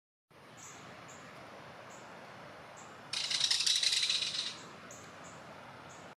Northern Rough winged Swallow startles or sound effects free download
Northern Rough-winged Swallow startles or is startled by kingfisher sound